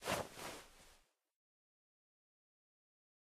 reload_end.ogg